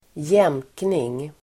Ladda ner uttalet
Uttal: [²j'em:kning]